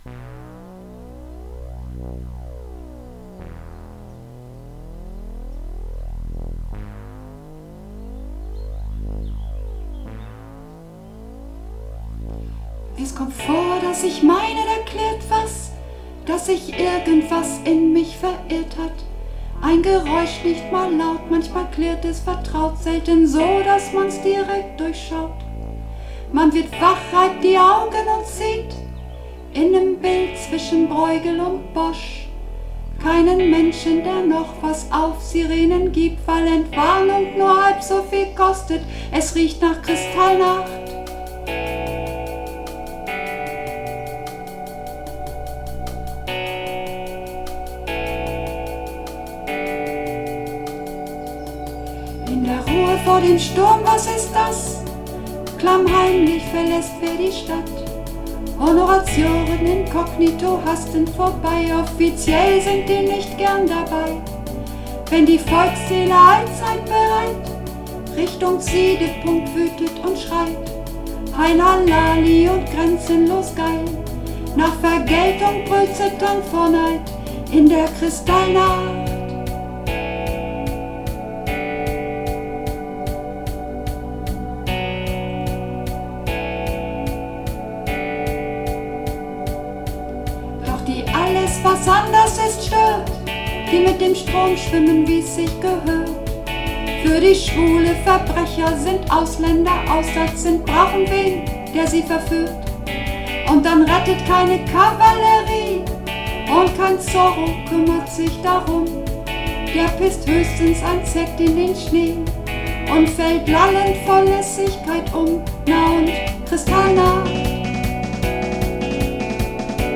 Runterladen (Mit rechter Maustaste anklicken, Menübefehl auswählen)   Kristallnacht (Sopran)
Kristallnacht__3b_Sopran.mp3